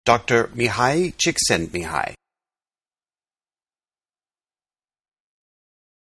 But I did learn to pronounce it.